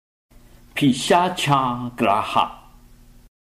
唱誦